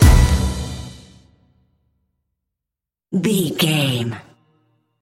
Aeolian/Minor
drum machine
synthesiser
hip hop
Funk
neo soul
acid jazz
energetic
bouncy
funky